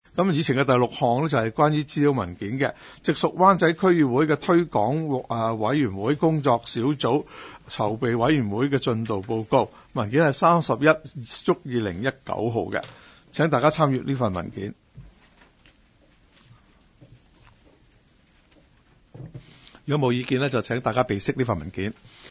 区议会大会的录音记录
湾仔区议会会议室